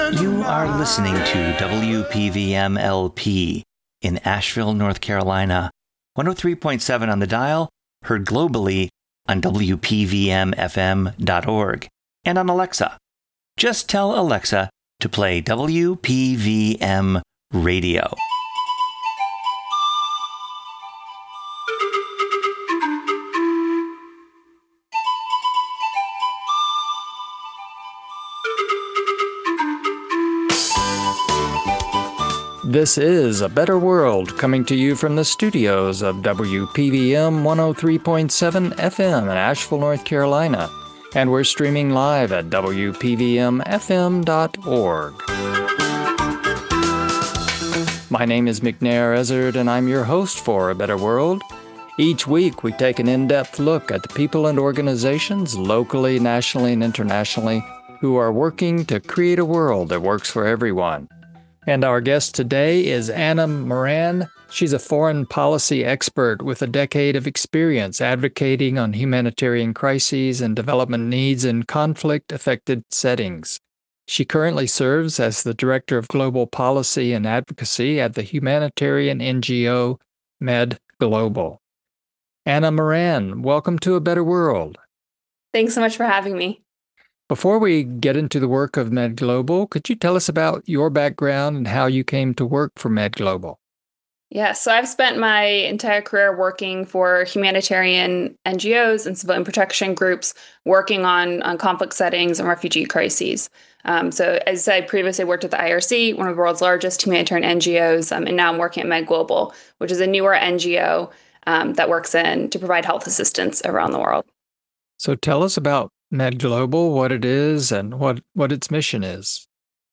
A Better World radio show